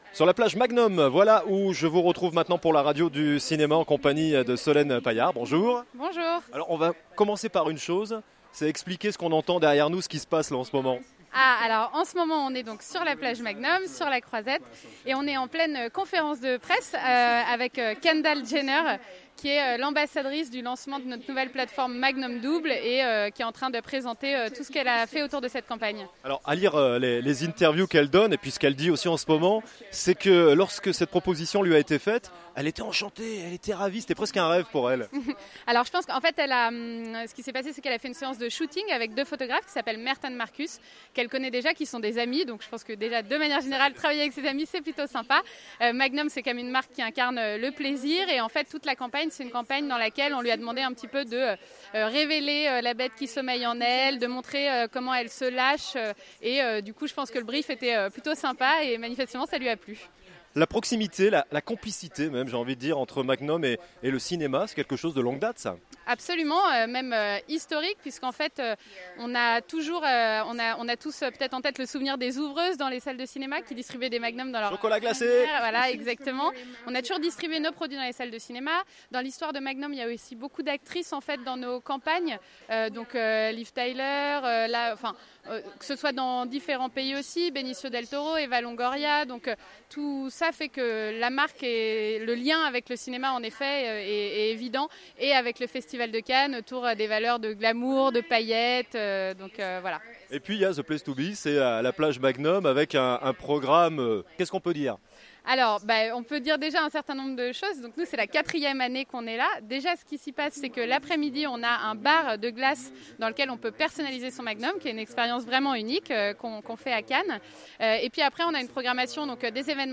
MAGNUM France - l'interview
Cannes 2017